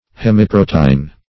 Search Result for " hemiprotein" : The Collaborative International Dictionary of English v.0.48: Hemiprotein \Hem`i*pro"te*in\, n. [Hemi- + protein.]
hemiprotein.mp3